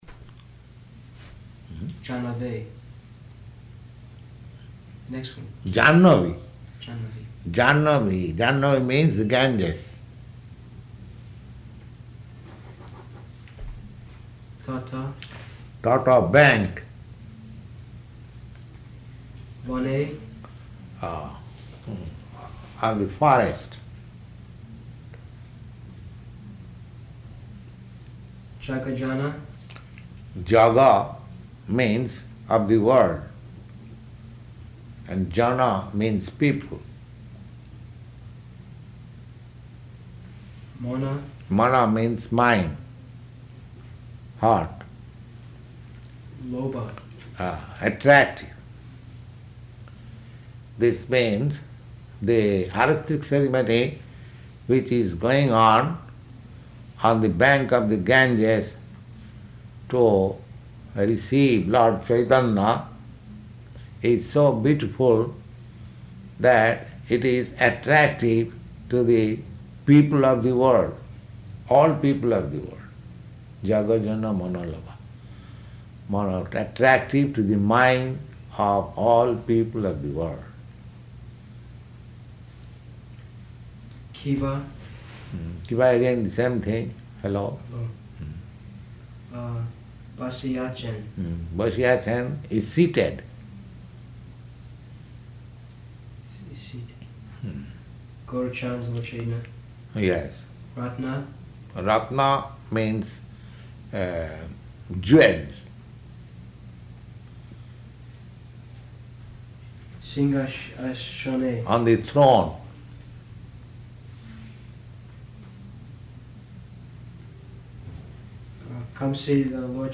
Type: Purport
Location: Los Angeles